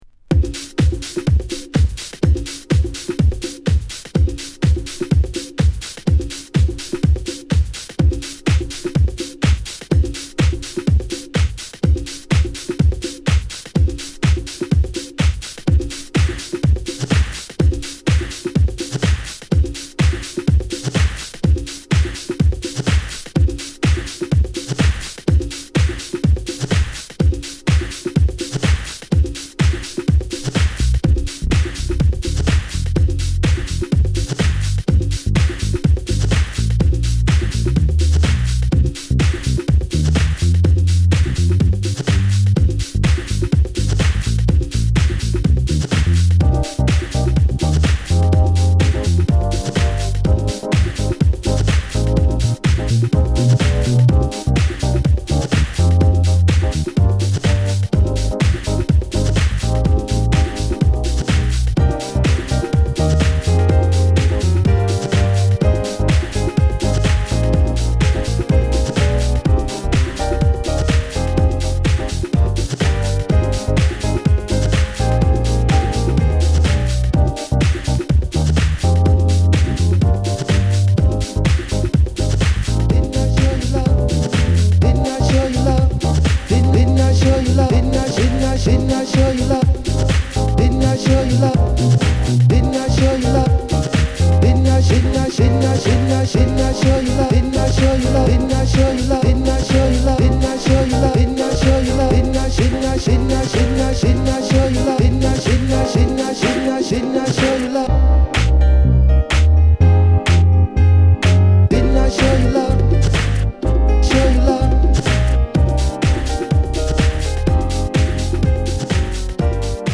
(Vocal Remix)
Garage Demo Mix)